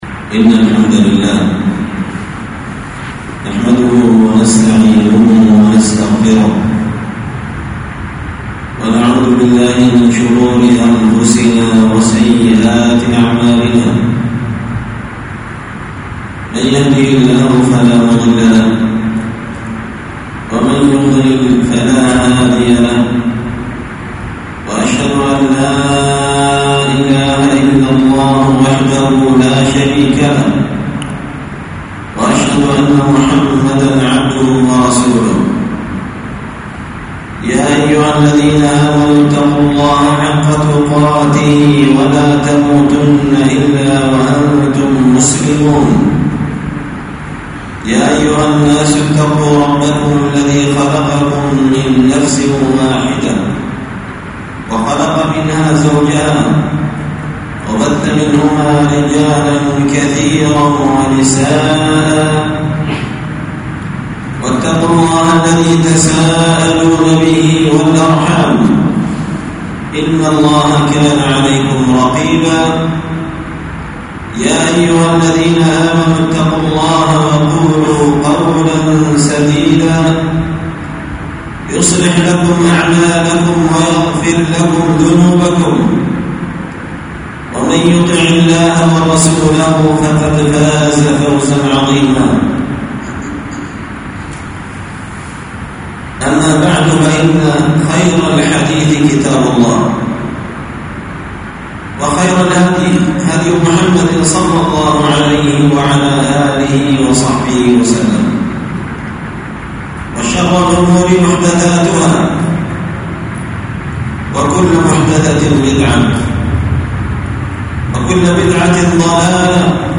خطبة جمعة
ألقيت هذه الخطبة بمسجد الصحابة بإسكان الجيش الغيضة -المهرة-اليمن